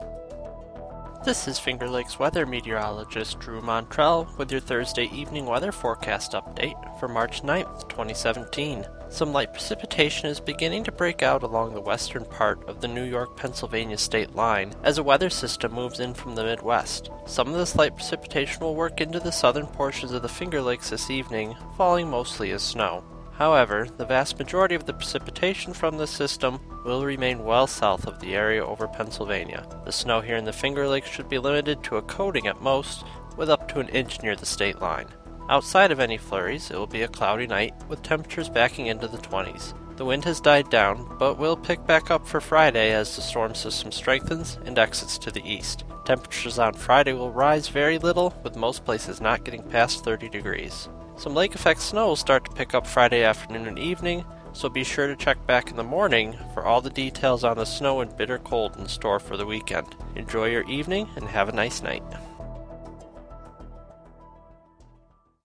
Evening Weather Forecast: March 9 Audio Transcript